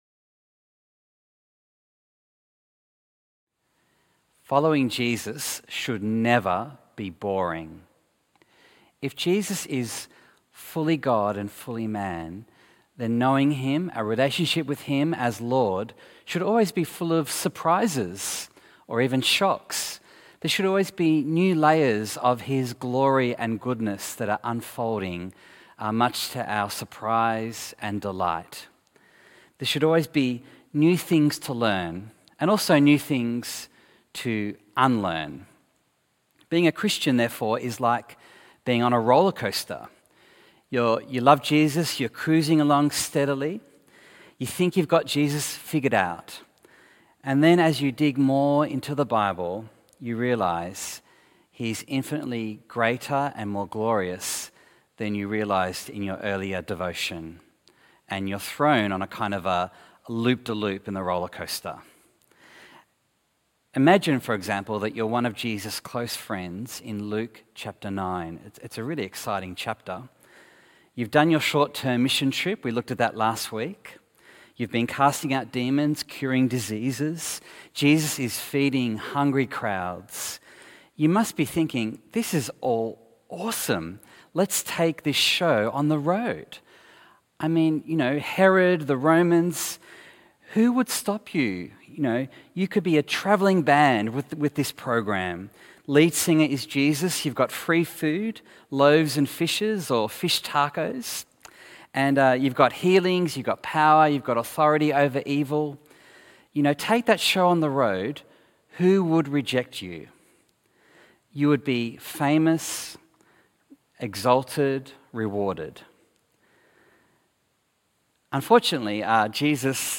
Bible Text: Luke 9:18-36 | Preacher